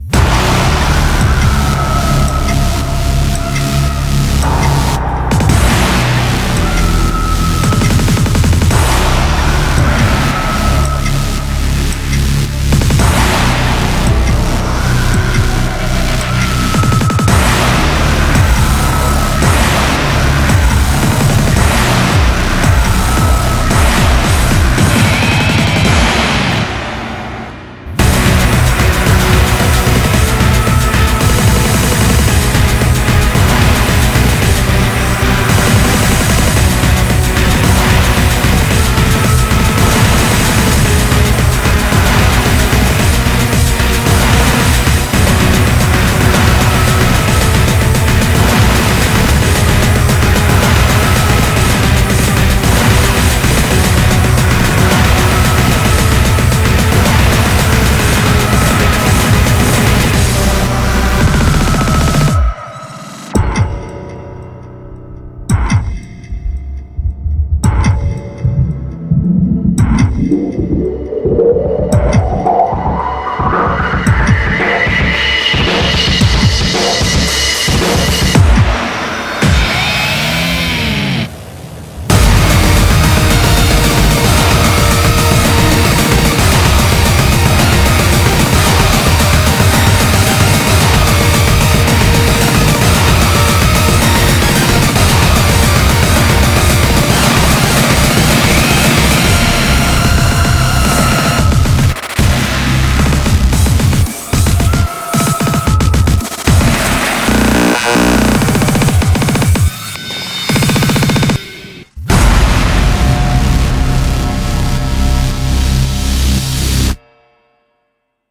BPM56-224